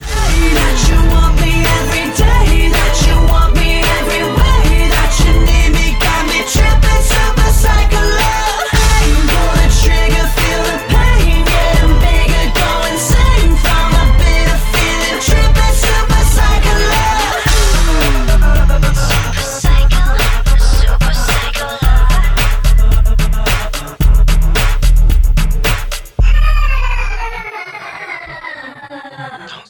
• Качество: 128, Stereo
поп
мужской вокал
громкие
красивая мелодия